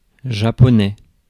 Ääntäminen
France: IPA: [ʒa.po.nɛ]